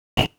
hitmob.wav